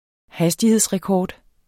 Udtale [ ˈhasdiheðs- ]